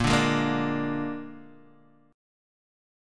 A#7 chord